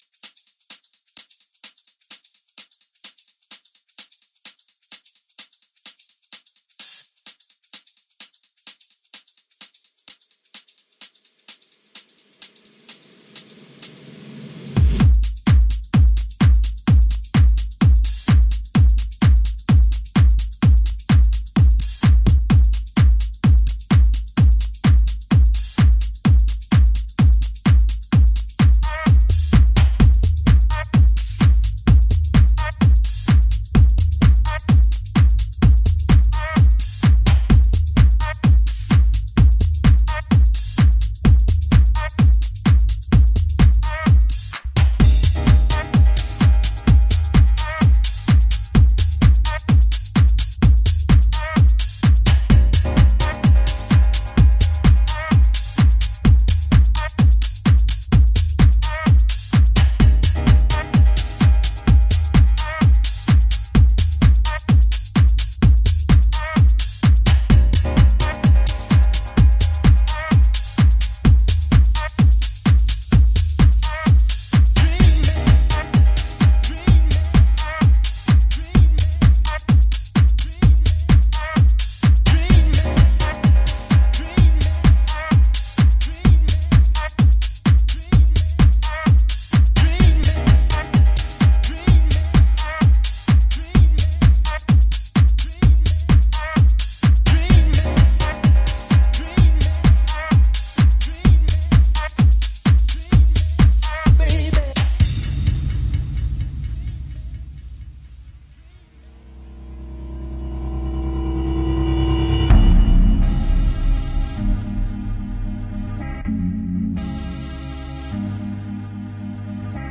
more energetic tech house sound with a great beat